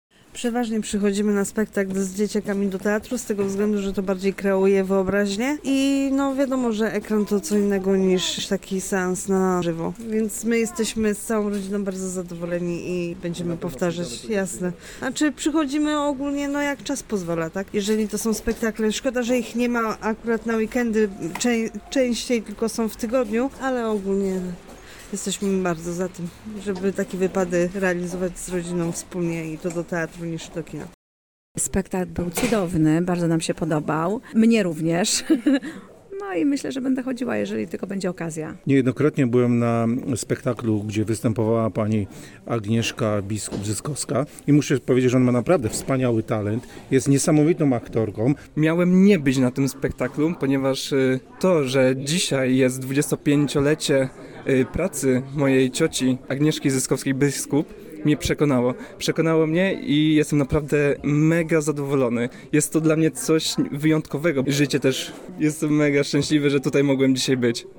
Spytaliśmy widzów o opinię na temat wystawionej sztuki:
Dzwiek-relacja-z-teatru.mp3